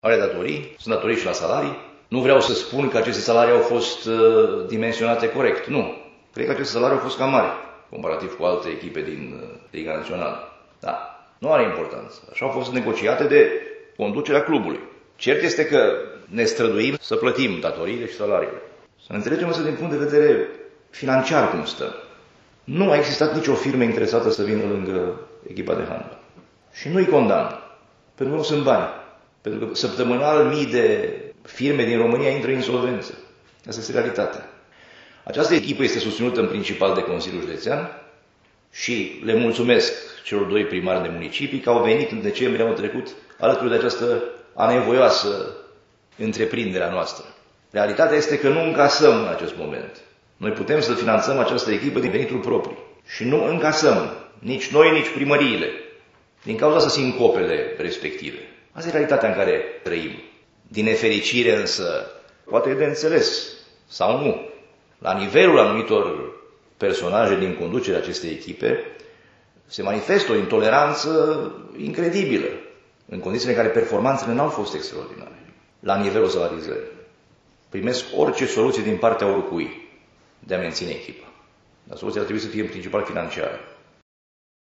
Ascultaţi declaraţia preşedintelui Sorin Frunzăverde: